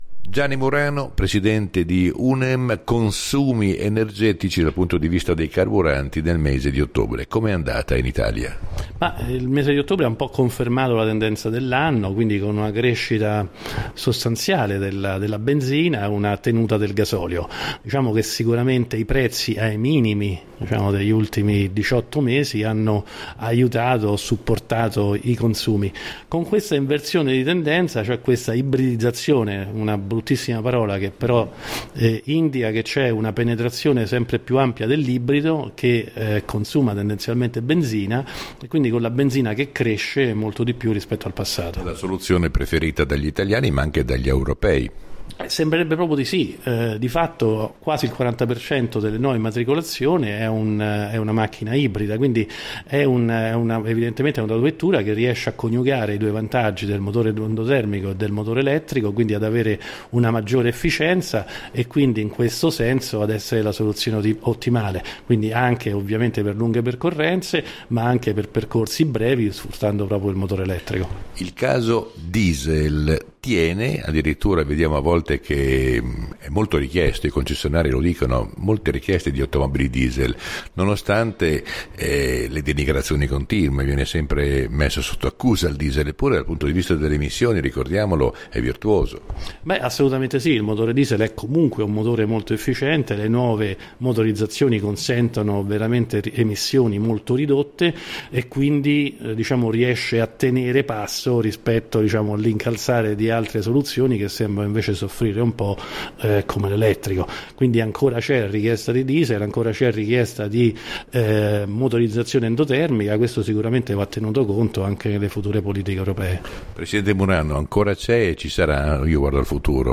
Aci Radio: intervista